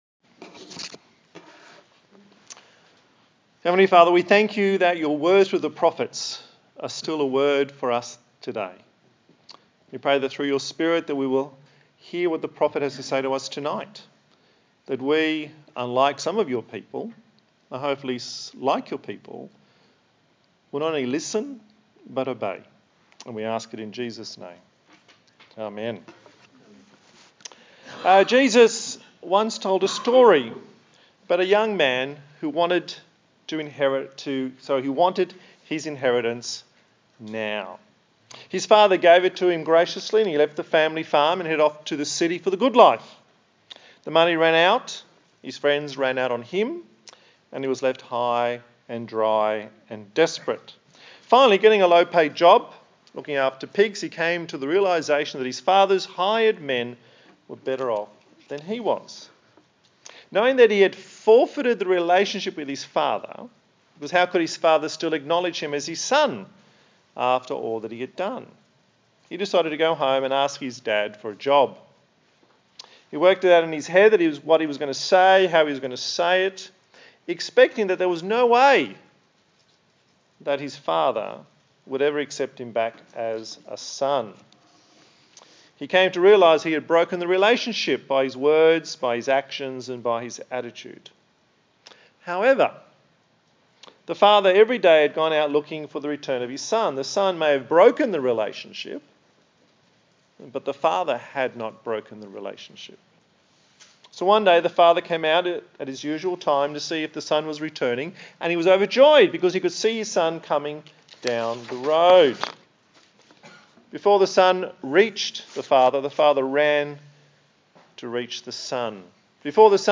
A sermon in the series on the book of Zechariah. The right response to God's Word is repentance. Headings: Vv 2-3 The call to return; Vv 4-5 Learn from the past; V 6 God's Word is abiding.